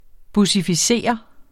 Udtale [ busifiˈseˀʌ ]